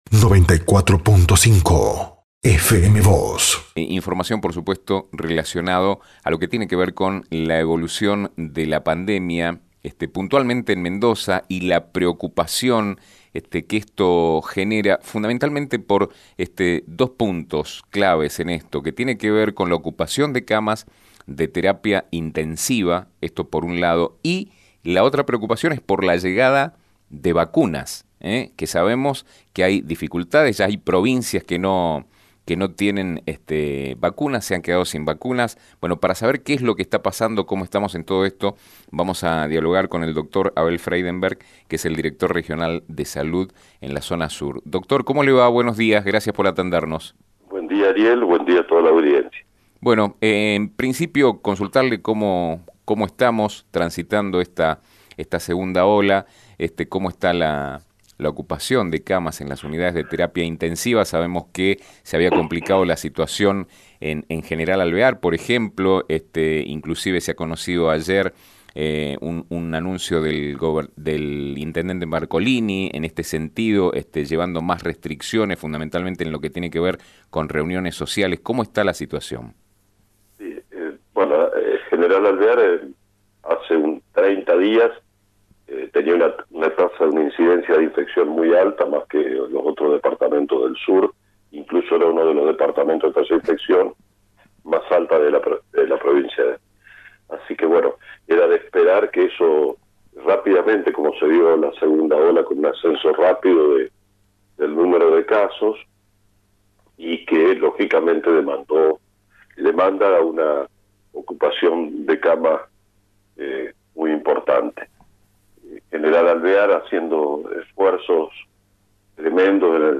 Sobre la realidad del sistema de salud con el que cuentan los alvearenses, el doctor Abel Freidemberg, director general de la Región Sur del Ministerio de Salud, Desarrollo Social y Deportes, dijo a FM Vos (94.5) y Diario San Rafael que “General Alvear hace 30 días tenía una tasa de incidencia de infección muy alta, más que en el resto del Sur de la provincia.
Abel-Freidemberg-1.mp3